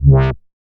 MoogVocaFilta B.WAV